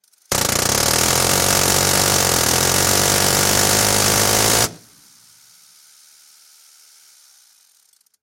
На этой странице собраны реалистичные звуки стрельбы из Минигана.
Звук непрерывной стрельбы из гатлинг-гана